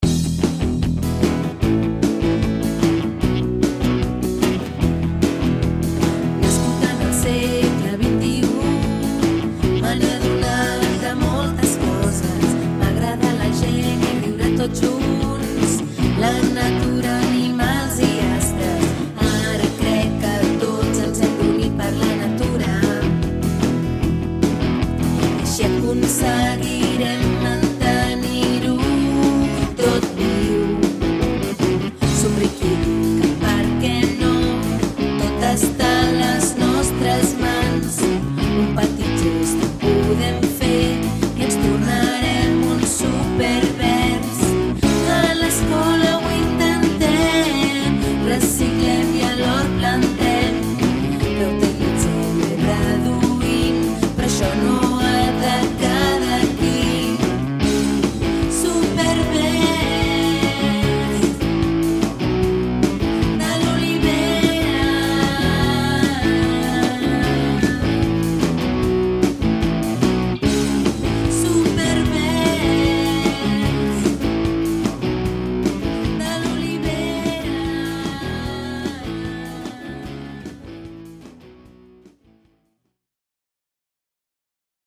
Festa Escola Verda
Finalment vam cantar plegats la